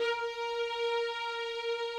strings_058.wav